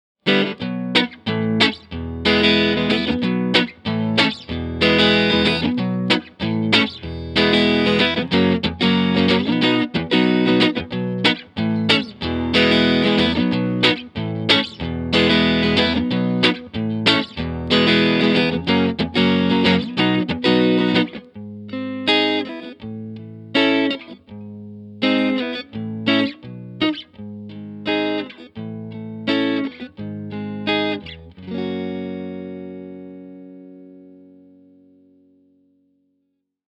Mic was a single SM57, to Vintech (Neve-style) pre, to Apogee Rosetta 200 A/D, to the computer.
Ch.1 Clean was as follows - no MV, cut 3:00, vol 7:30, contour pos 2 (from left), munch/hi on the back, Lo input on the front.
TC15_Ch1_Clean_Strat_Neck.mp3